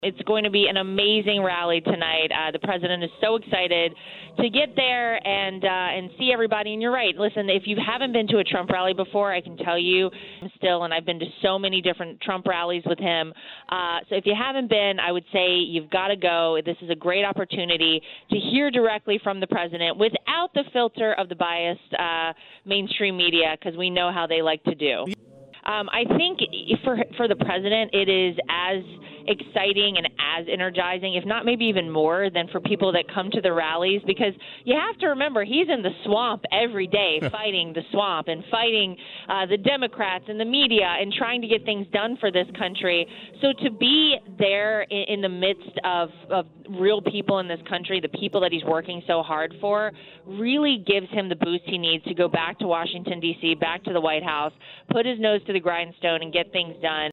Dallas (WBAP/KLIF) – As thousands of President Trump supporters lined up outside the American Airlines Center for campaign rally later today, our newsroom talked with Lara Trump, wife of Trump’s son Eric. She says Trump rallies are “electric.”
Listen below for more of Lara Trump: